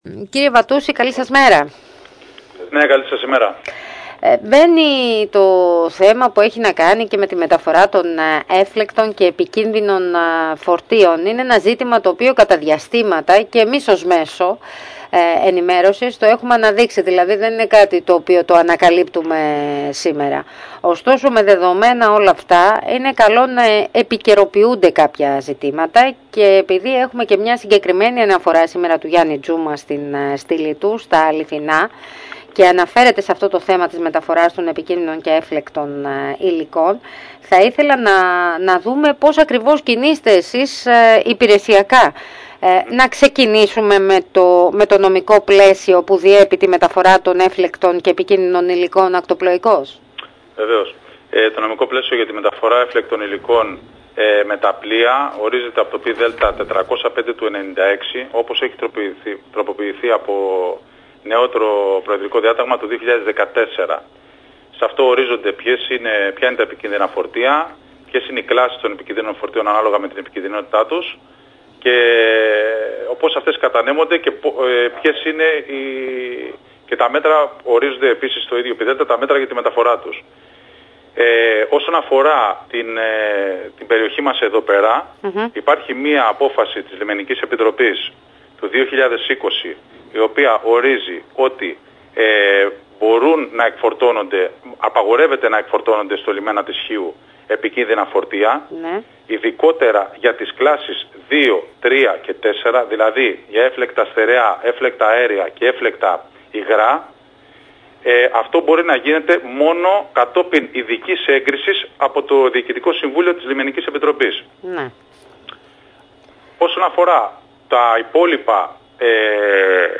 «Ακατάλληλο το λιμάνι» λέει ο Κεντρικός Λιμενάρχης Το θέμα των προσκρουστήρων των πλοίων και τα βάθη της λεκάνης του λιμανιού είναι τα δύο κυριότερα προβλήματα στα οποία αναφέρθηκε ο Κεντρικός Λιμενάρχης Χίου Παντελής Βατούσης, σε συνέντευξή του στο τοπικό μέσο.